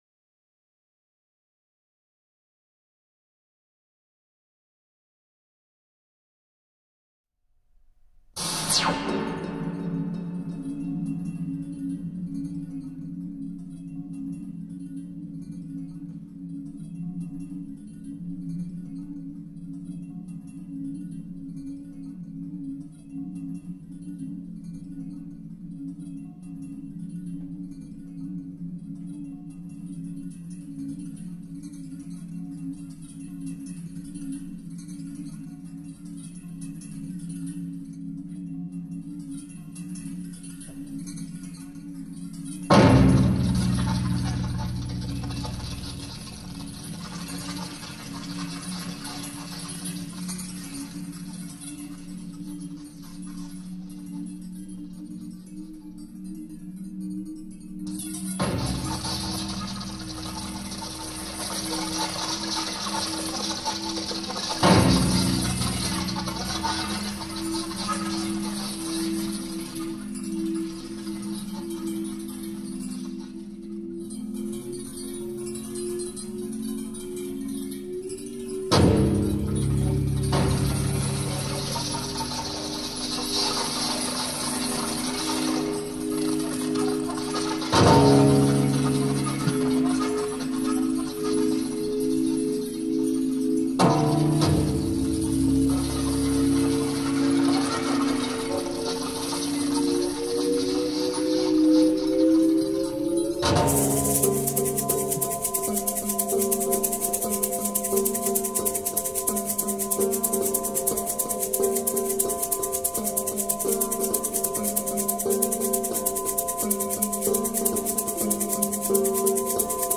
Javier Álvarez / Temazcal, pour maracas et électronique